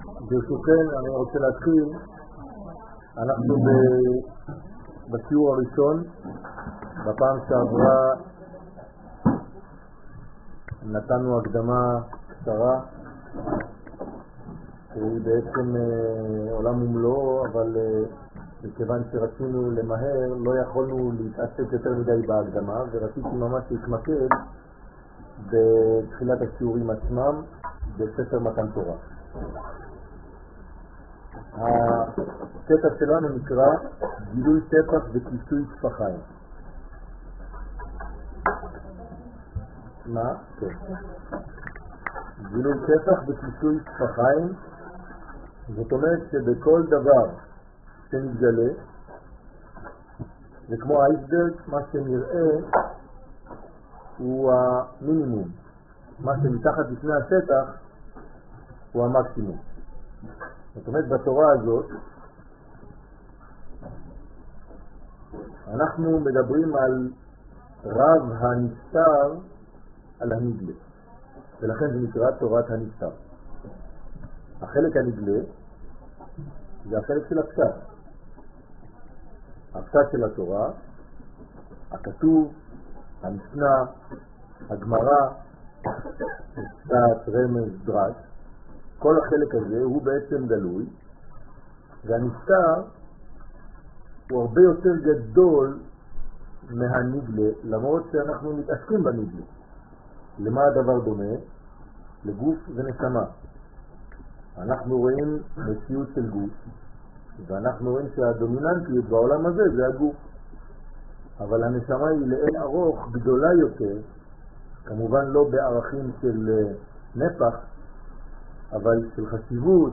מתן תורה שיעור שני גילוי טפח וכיסוי טפחיים 051 זהות שיעור מ 12 ספטמבר 2007 01H 10MIN הורדה בקובץ אודיו MP3 (64.93 Mo) הורדה בקובץ אודיו M4A (8.96 Mo) TAGS : עברית שיעורים תורה וזהות הישראלי שיעורים קצרים